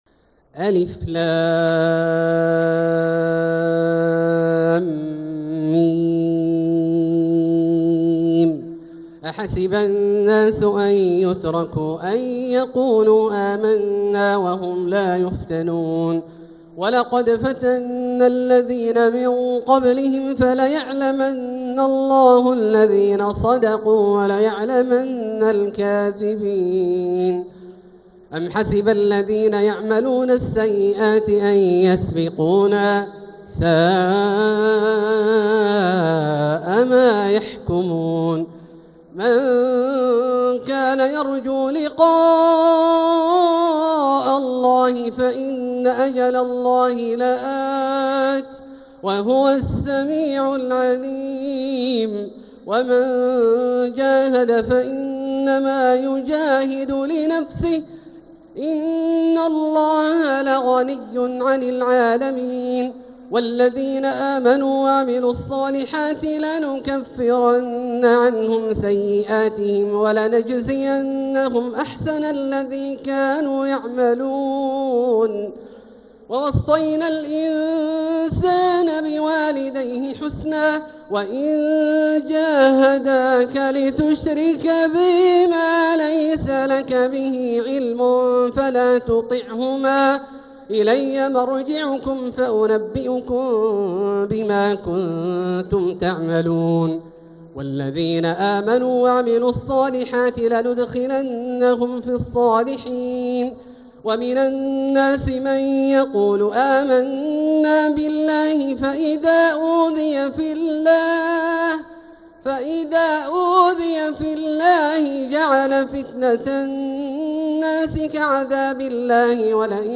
تلاوة تدبرية ندية لـ سورة العنكبوت كاملة للشيخ د. عبدالله الجهني من المسجد الحرام | Surat Al-Ankabut > تصوير مرئي للسور الكاملة من المسجد الحرام 🕋 > المزيد - تلاوات عبدالله الجهني